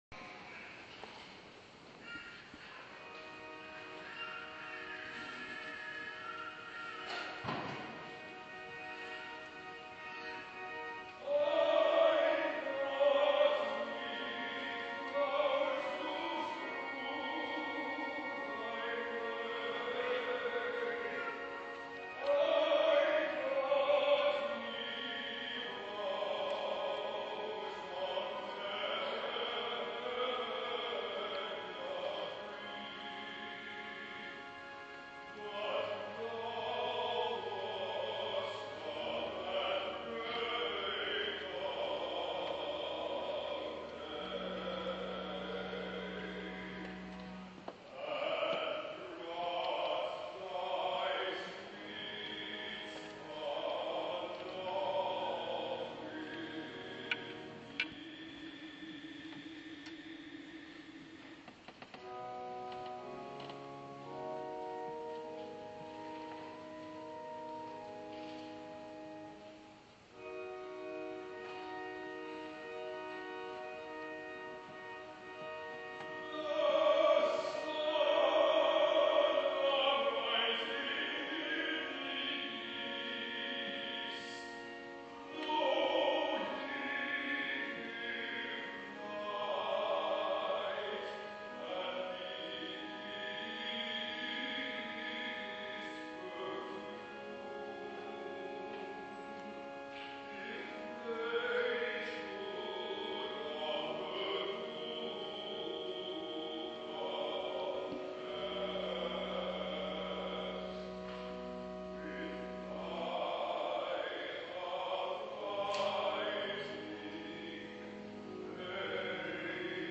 I Got Me Flowers to Strew Thy Way, from the Five Mystical Songs by Vaughan Williams: performed at my church on Easter Sunday 2017
I absolutely love this anthem.
Too bad about the baby crying at the beginning, but that doesn't last long.